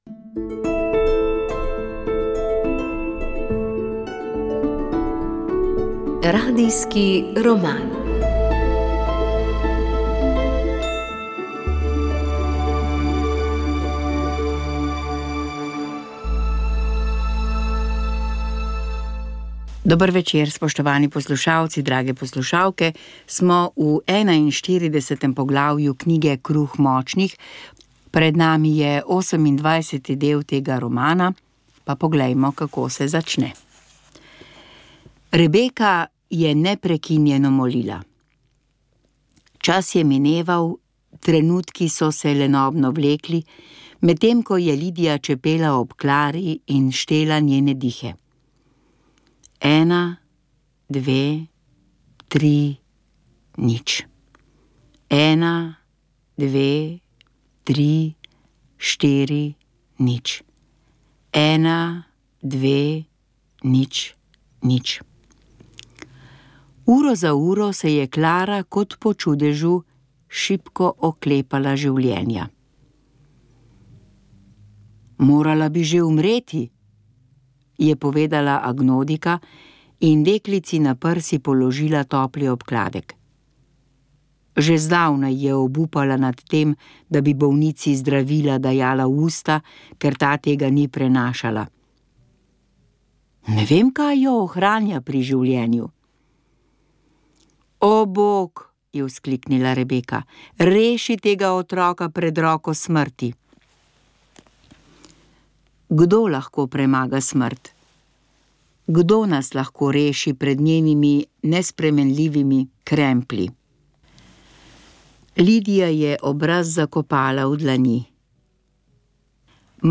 Naša želja je bila odpreti prostor za iskren in spoštljiv pogovor med teisti in ateisti. Zadnja oddaja povzema ključna spoznanja, ki jih je prinesel dialog, besedo pa je imelo tudi občinstvo, ki se je zbralo ob snemanju oddaje.